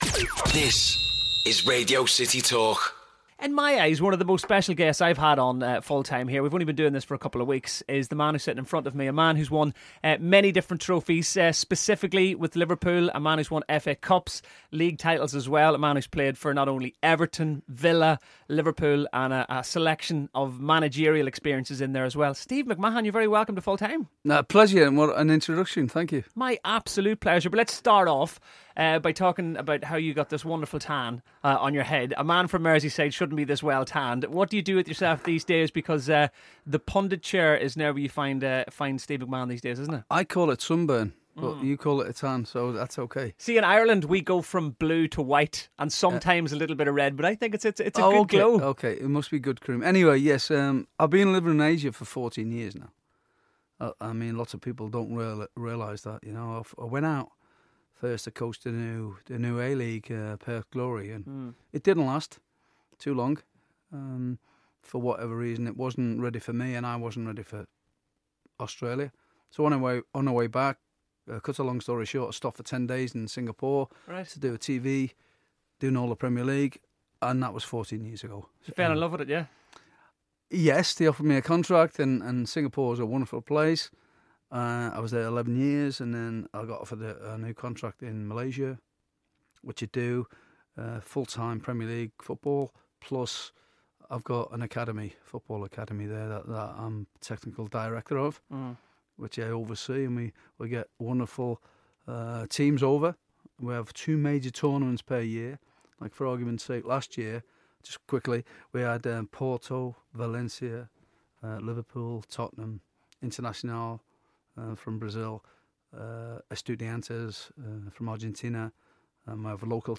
Steve McMahon Interview